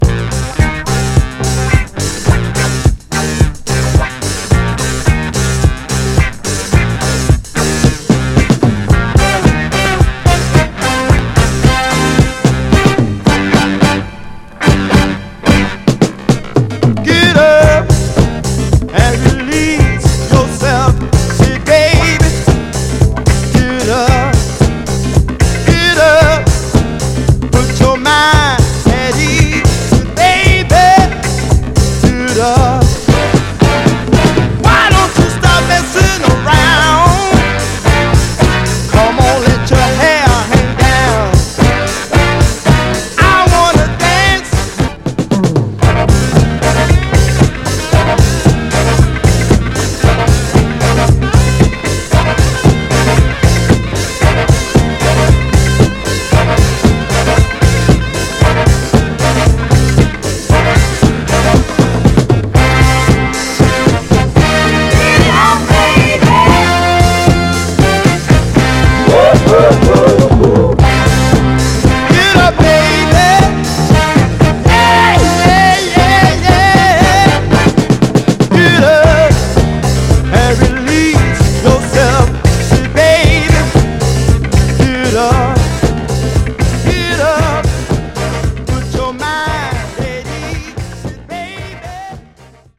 ※試聴音源は実際にお送りする商品から録音したものです※